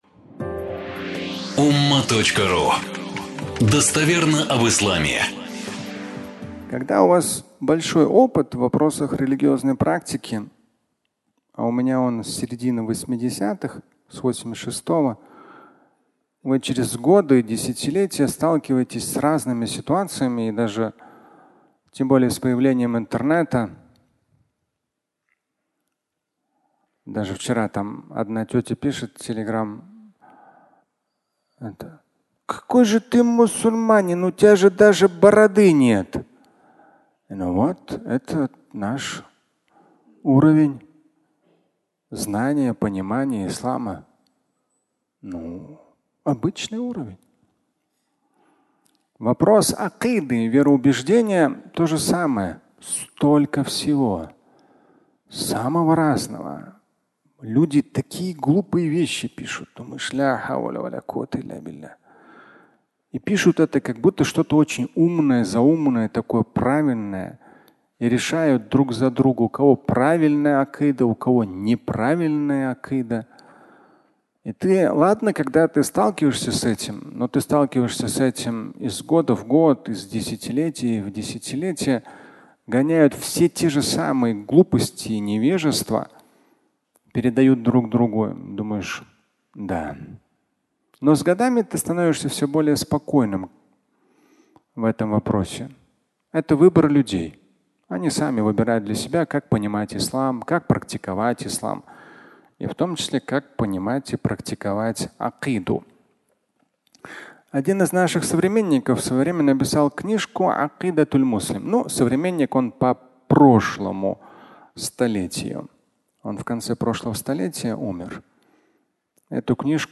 Акыда. Источник силы (аудиолекция)